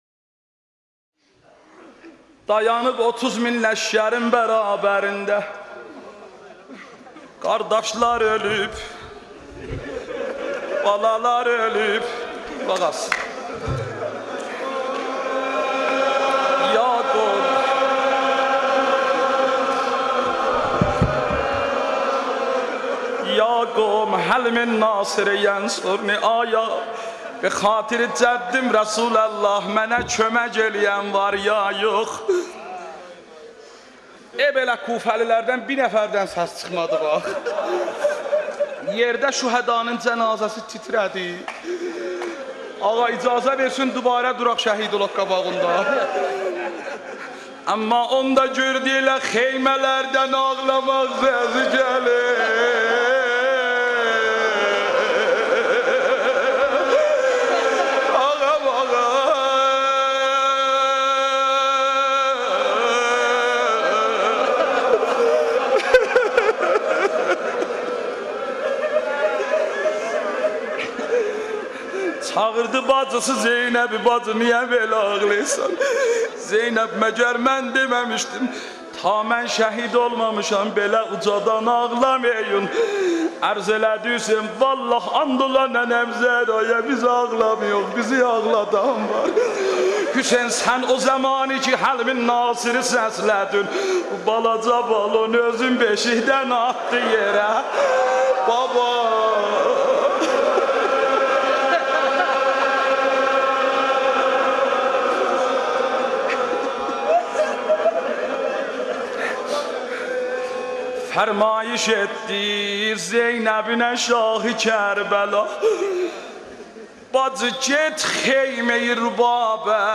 دانلود مداحی ترکی حضرت علی - نوحه آذری شهادت حضرت علی (ع)